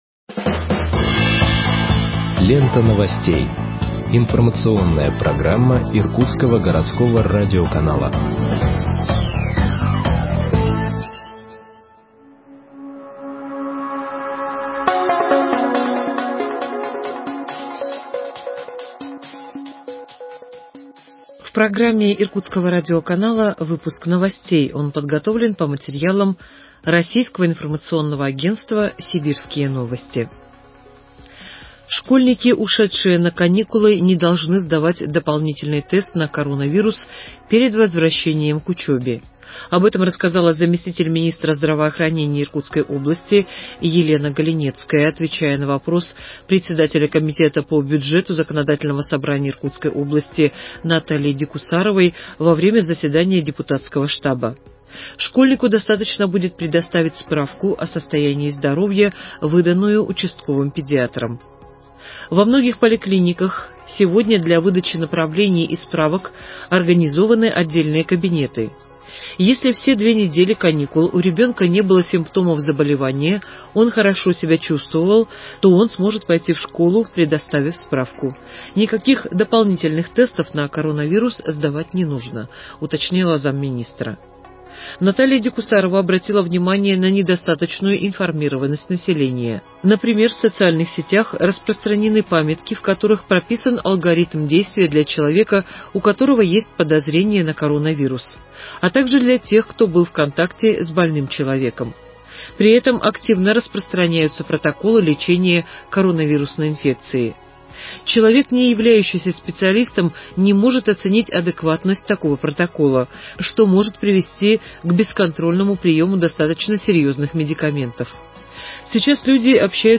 Выпуск новостей в подкастах газеты Иркутск от 30.10.2020 № 2